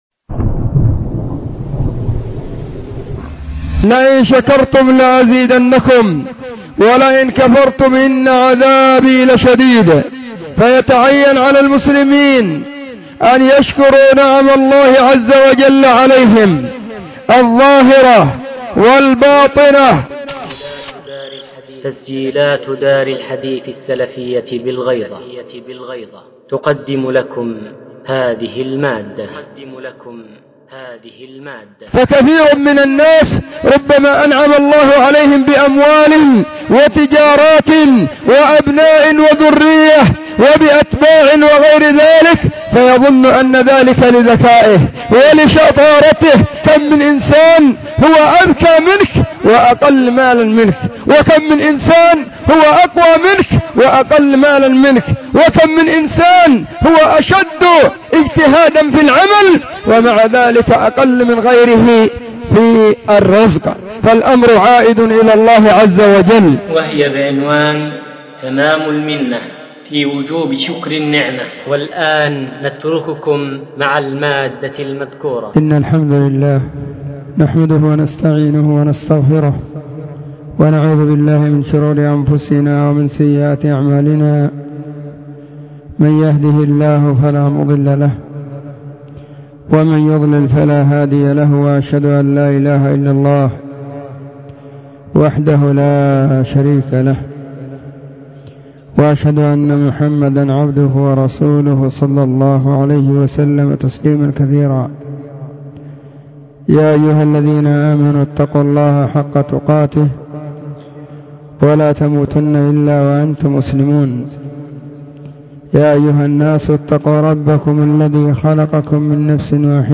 🎙 فهذه خطبة جمعة بعنوان : تمام المنة في وجوب شكر النعمة
📢 وكانت – في – مسجد – الصحابة – بالغيضة – محافظة – المهرة – اليمن.